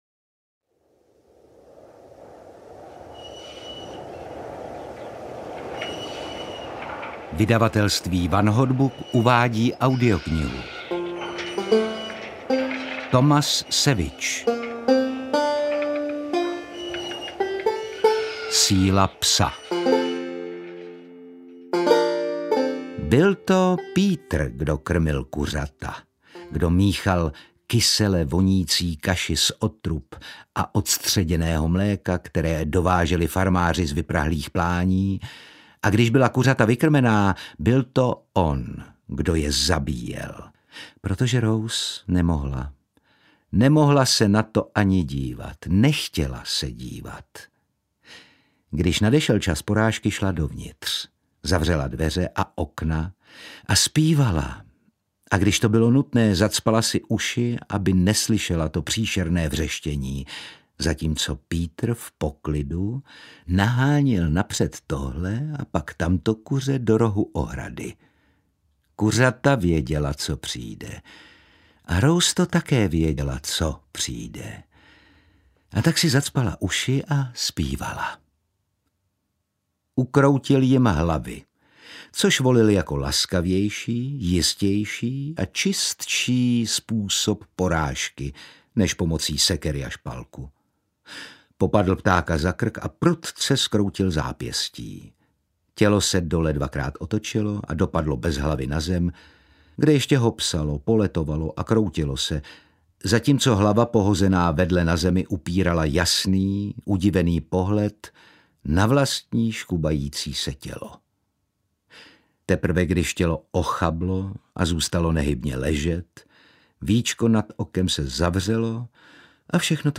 Síla psa audiokniha
Ukázka z knihy
• InterpretOtakar Brousek ml.